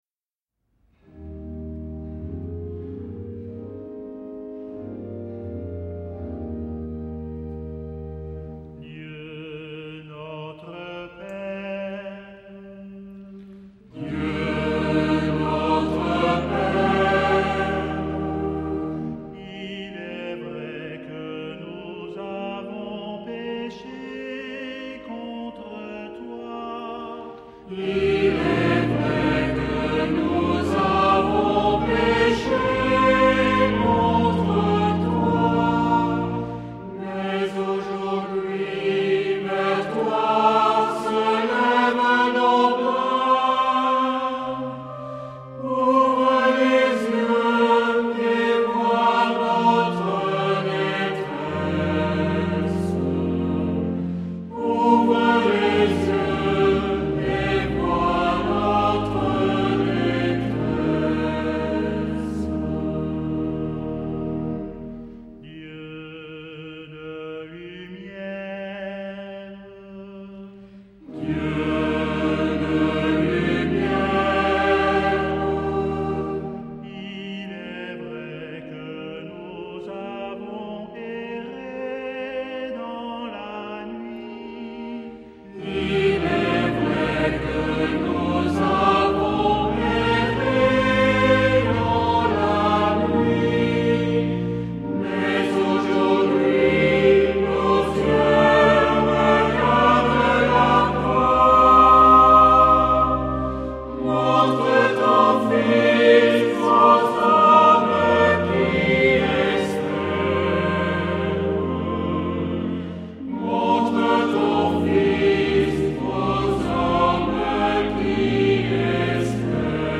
Genre-Style-Forme : Prière ; Invocation
Caractère de la pièce : suppliant ; recueilli ; calme
Type de choeur : SATB  (4 voix mixtes + assemblée )
Instrumentation : Orgue  (1 partie(s) instrumentale(s))
Tonalité : mi bémol majeur